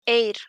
Eir [ˈɛir] = pronuncia Ai-rh